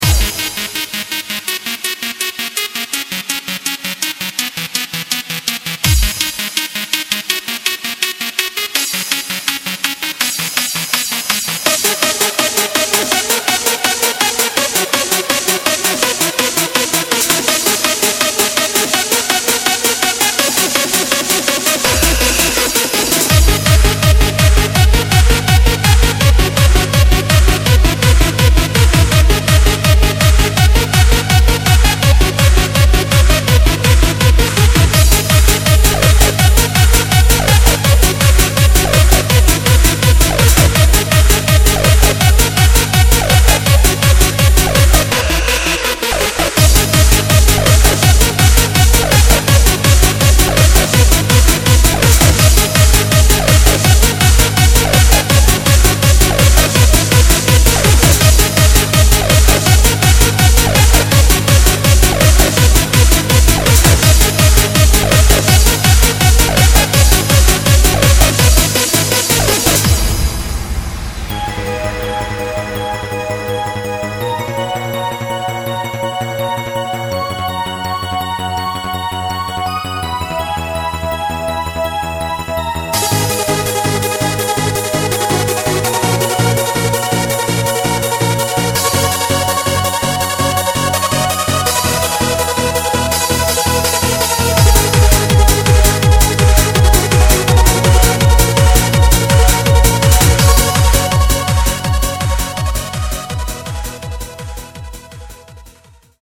Hard-Trance
First-class Hard-Trance at it´s best…